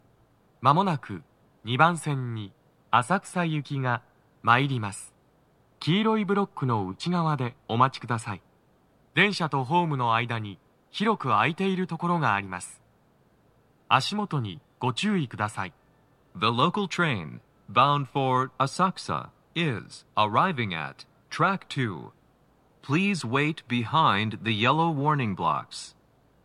スピーカー種類 TOA天井型
鳴動は、やや遅めです。
2番線 上野・浅草方面 接近放送 【男声